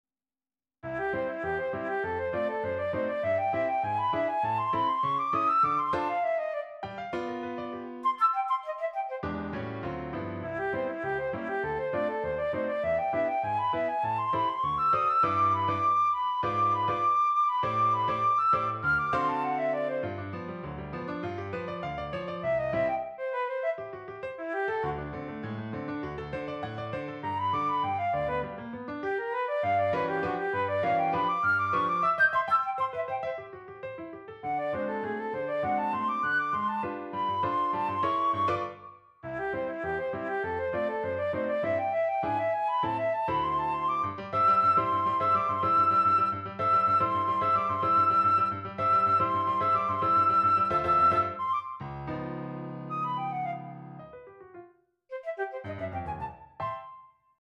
Shop / Noten / Flöte und Klavier
• 11 Stücke für Flöte und Klavier